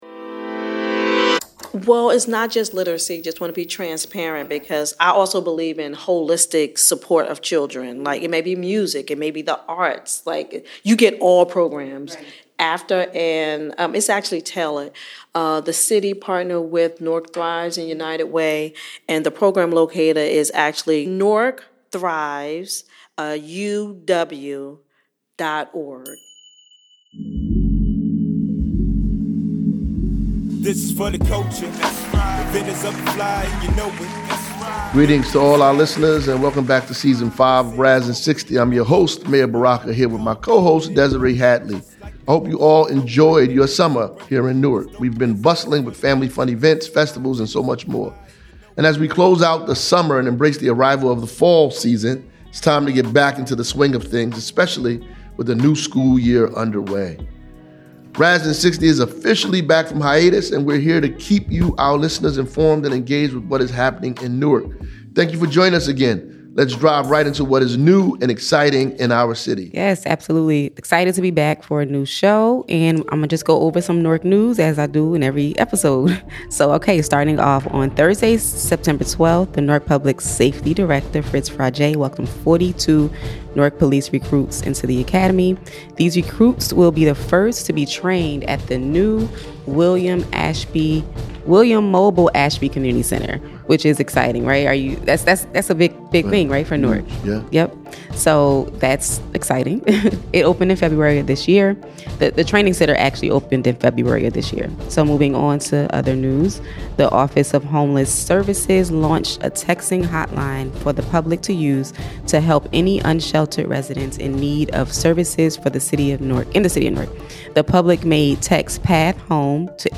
kick things off with a detailed conversation about affordable housing